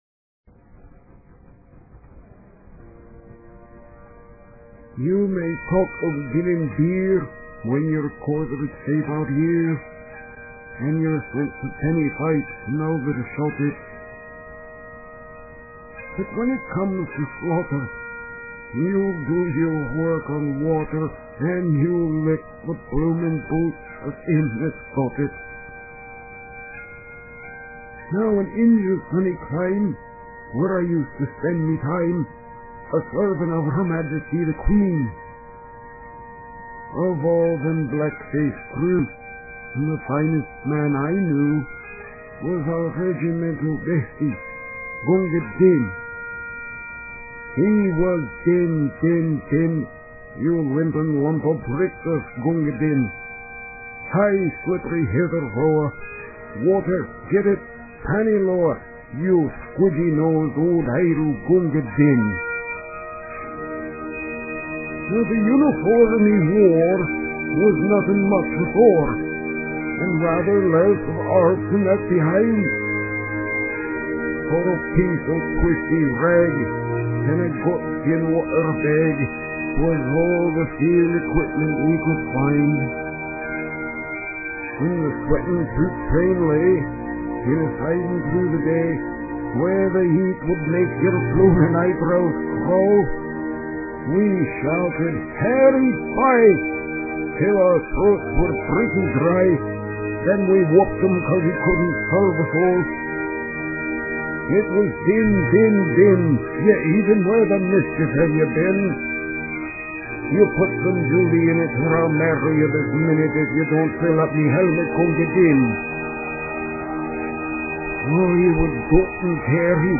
Being compressed, they sound "tinny" but the original MP3 files sound just fine and I've burnt them on to CD's to play in car stereos and such.
The bagpipe rendition of  "Amazing Grace" is by the 48th Scot Dragoon Guards.
This was my first ever attempt at recording my voice to a music rendition.
Gunga-Din was done using a trial-version of a small recorder program and I tried to sound like an elderly Scottish soldier recounting his glory days to young, new recruits.